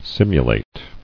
[sim·u·late]